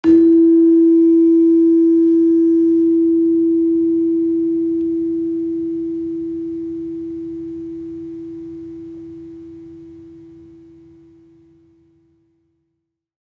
Gamelan Sound Bank
Gender-1-E3-f.wav